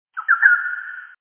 A notepad window that skreeks like a bat